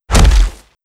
Player_FallImpact.wav